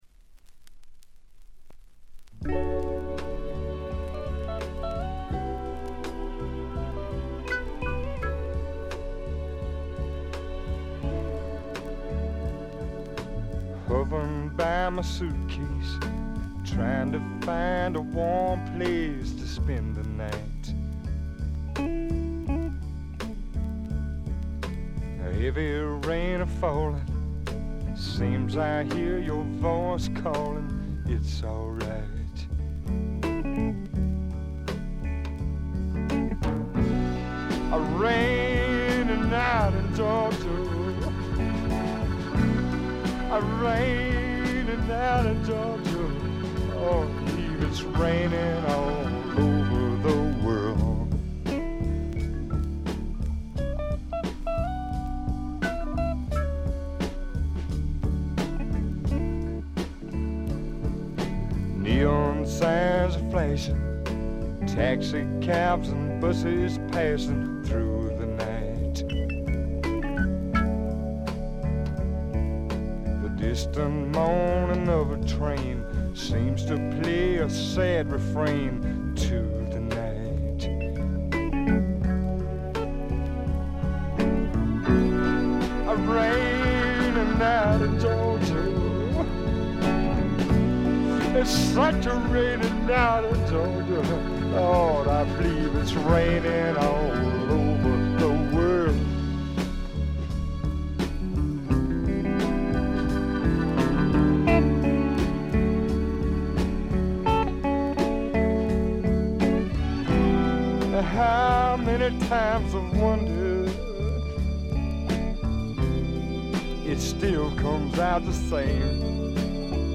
ところどころでチリプチ。散発的なプツ音。
試聴曲は現品からの取り込み音源です。